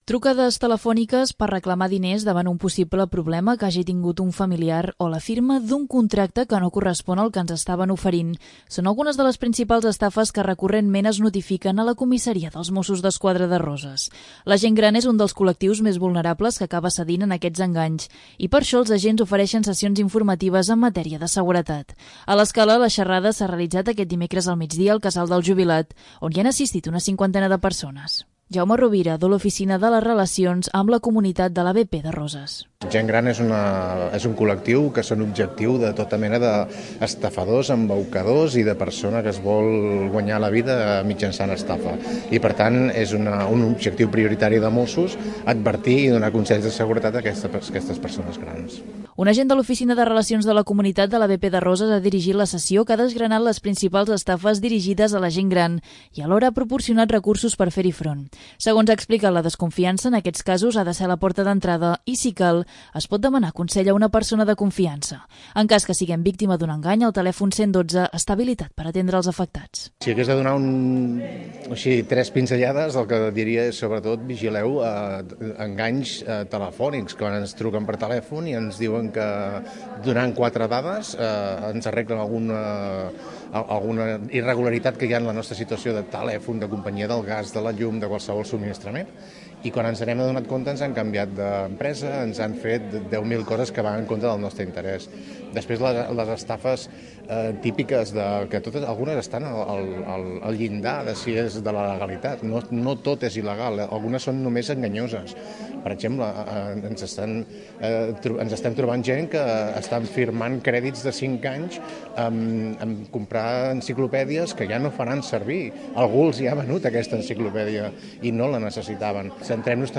Una cinquantena d'usuaris del programa +60 han assistit aquest dimecres a una sessió informativa dels Mossos d'Esquadra sobre consells de seguretat. Un agent de l'ABP de Roses ha explicat que 'la gent gran és un dels objectius de tota mena d'estafadors', i ha revelat les principals problemàtiques que es pot trobar el col·lectiu.